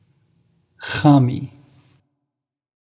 Ezai Pronunciation
The “kh/qh” sound does not have an English equivalent.
The best way to describe it is as the sound you make when clearing your throat.